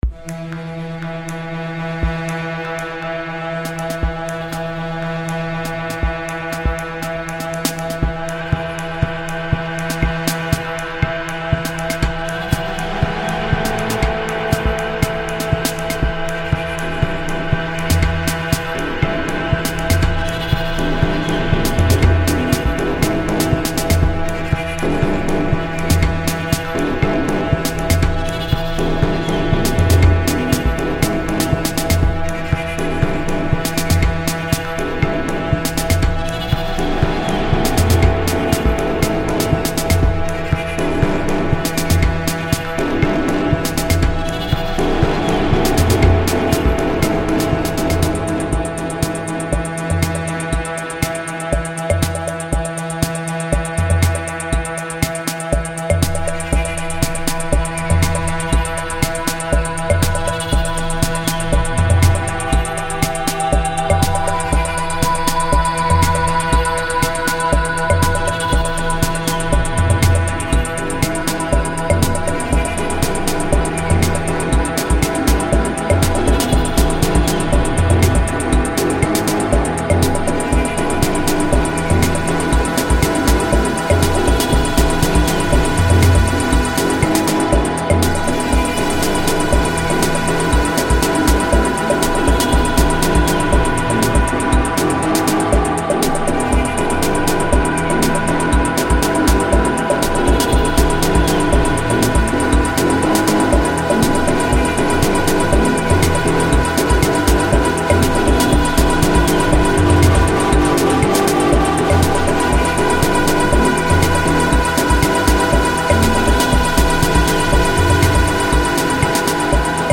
I always make it as multi-layered and processed as it can get to the point of being incomprehensible. And I started my path from industrial/noise — much darker stuff.